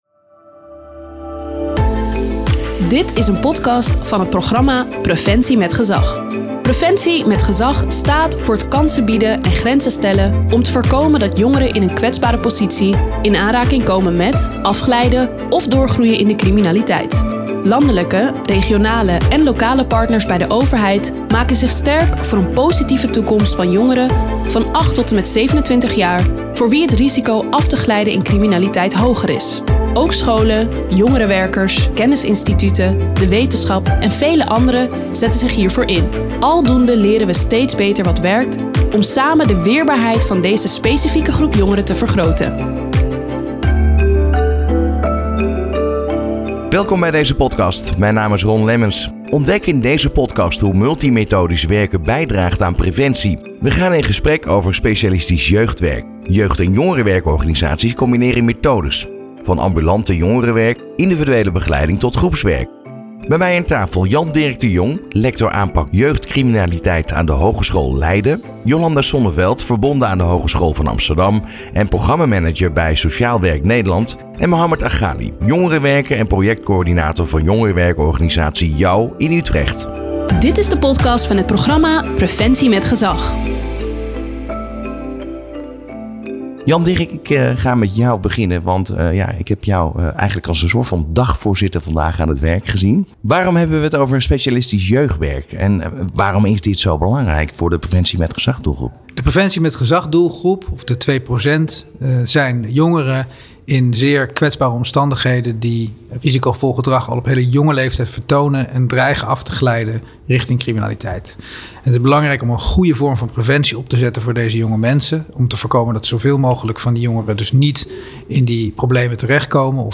In de eerste podcast gaan we in gesprek over specialistisch jeugdwerk. 'Jeugd- en jongerenwerkorganisaties combineren methodes, van ambulant jongerenwerk, individuele begeleiding tot groepswerk. Ontdek hoe multi-methodisch werken bijdraagt aan preventie.